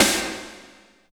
49.08 SNR.wav